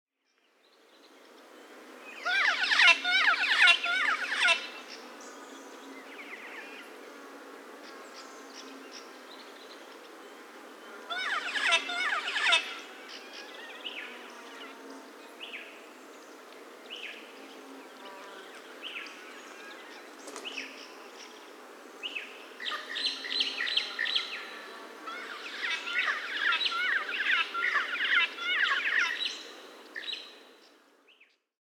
Blac-faced cuckoo shrike
Coracina novaehollandiae